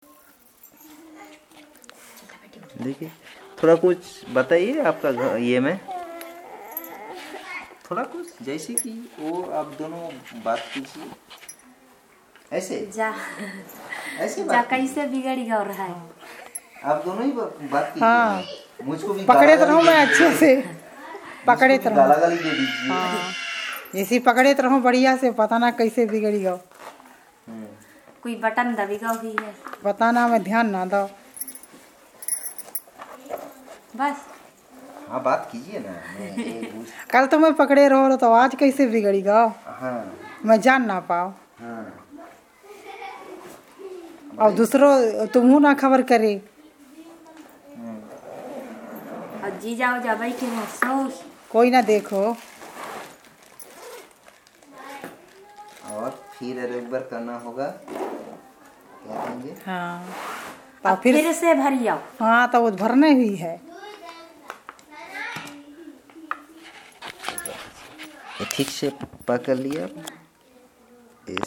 Conversation about random things